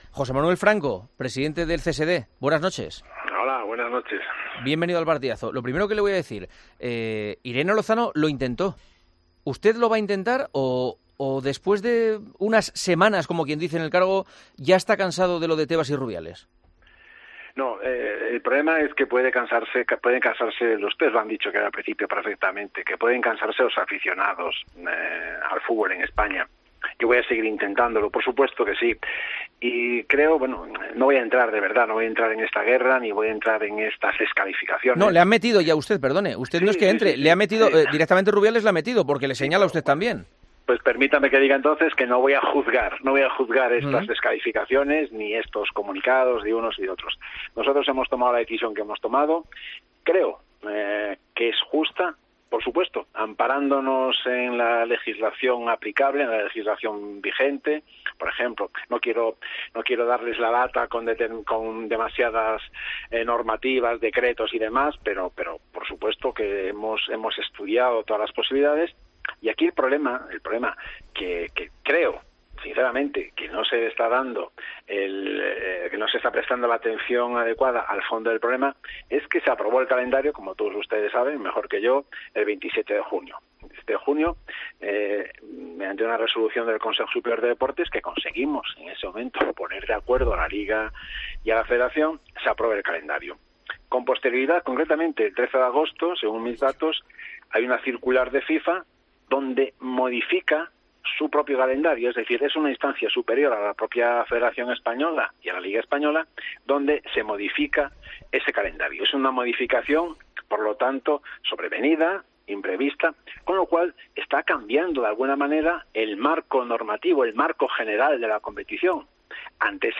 AUDIO - ENTREVISTA A JOSÉ MANUEL FRANCO, PRESIDENTE DEL CSD, EN EL PARTIDAZO DE COPE